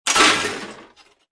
descargar sonido mp3 caida 6